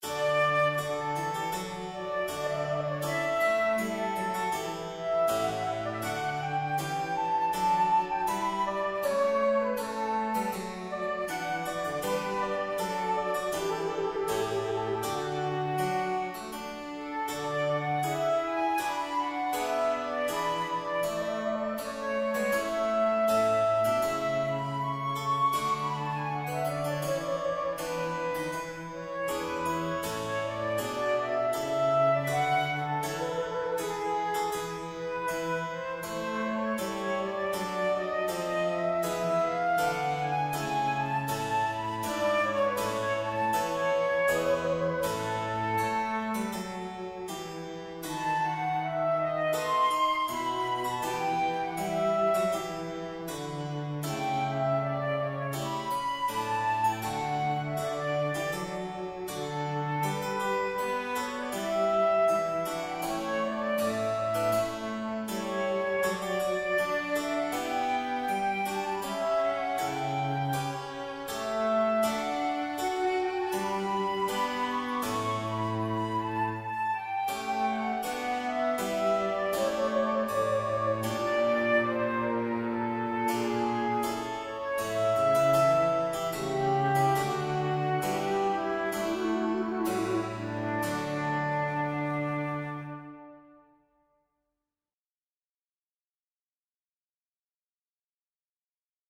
Flute Sonata in D major (Hasse, Johann Adolph) - IMSLP
For flute, continuo
Synthesized/MIDI